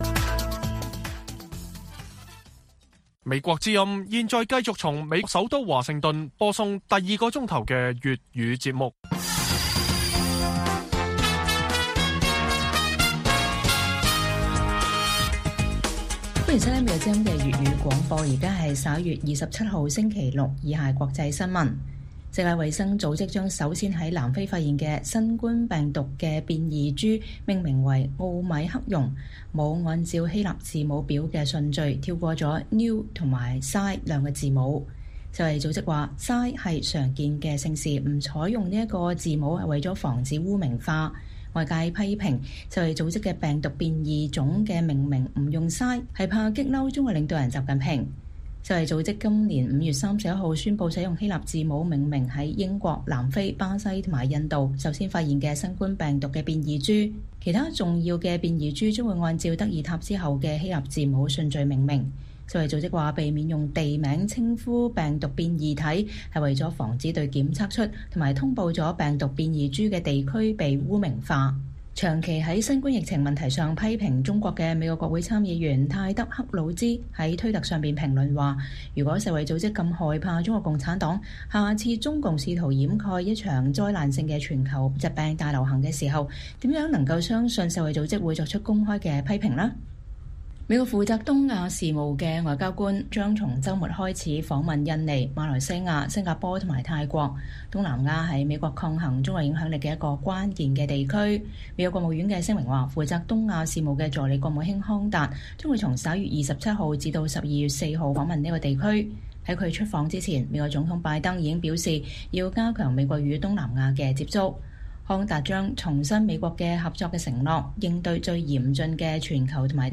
粵語新聞 晚上10-11點:怕犯了誰的名諱？世衛命名病毒新變種跳過希臘字母Xi